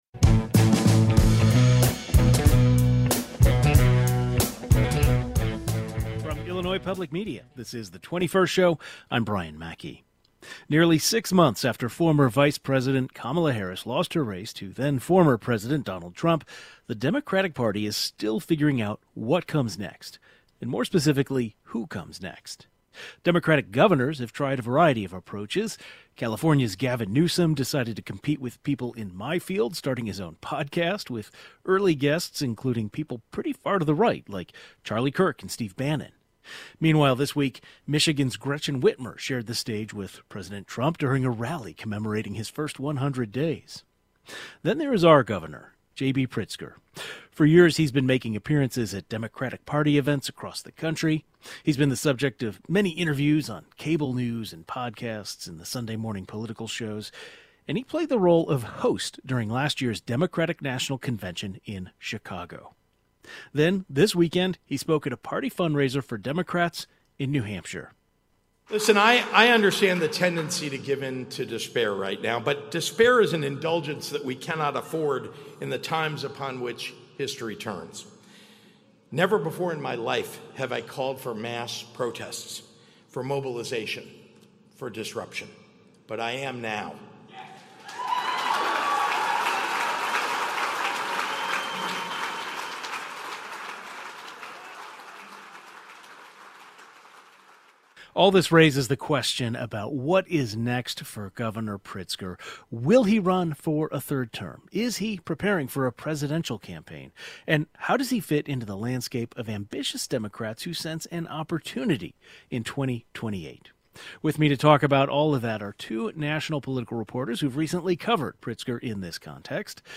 Two journalists who have recently covered Pritzker in this context share their thoughts.